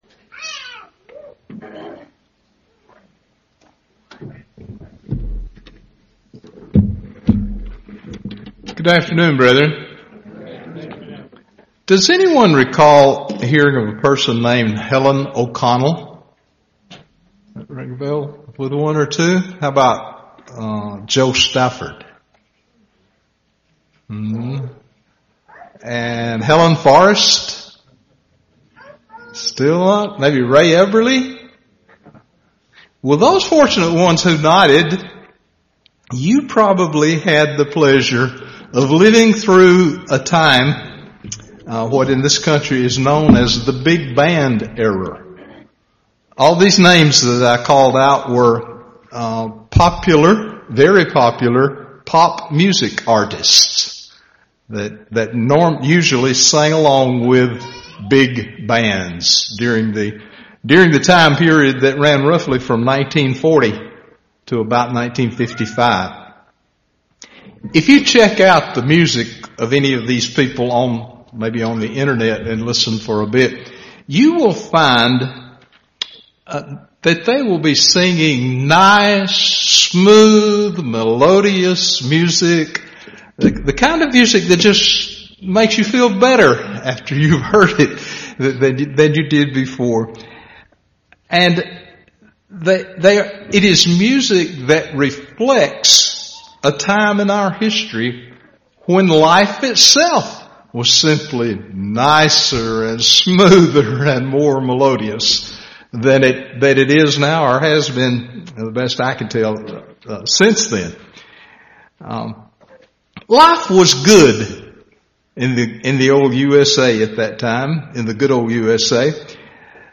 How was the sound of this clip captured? Given in Birmingham, AL